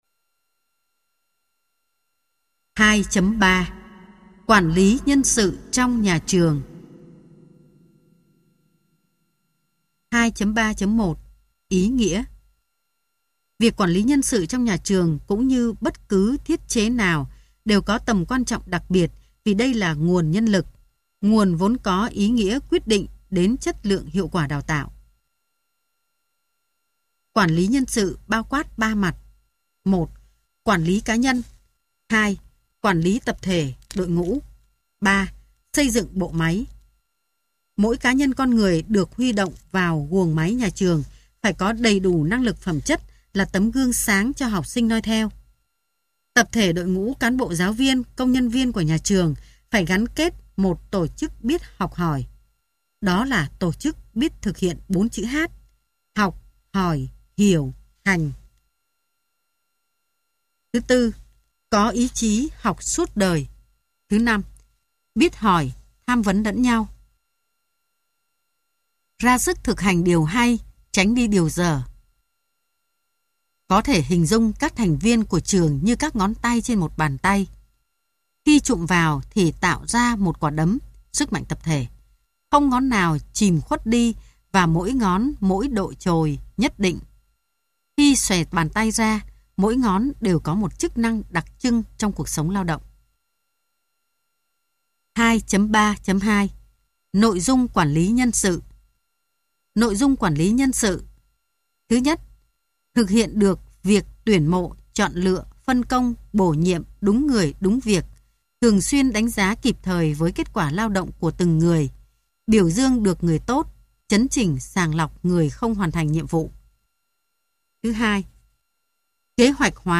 Sách nói Khoa Học Quản Lí Đại Cương - TS Nguyễn Thành Vinh - Sách Nói Online Hay
Khoa Học Quản Lí Đại Cương Tác giả: TS Nguyễn Thành Vinh Nhà xuất bản Giáo Dục Việt Nam Giọng đọc: nhiều người đọc